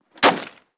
努纳维克 " 枪 李恩菲尔德 303步枪射击
描述：枪李恩恩菲尔德303步枪射击